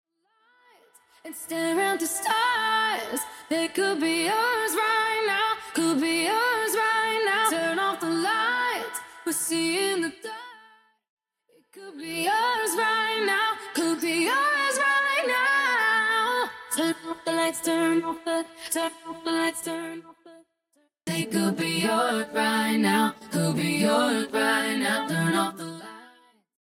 (Studio Backing Vocals Stem)
(Studio Bassline Stem)
(Studio Leading Vocals Stem)
(Studio Violin & Synth Stem)
(Studio Vocoder 1 Stem)